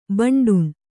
♪ baṇḍuṇ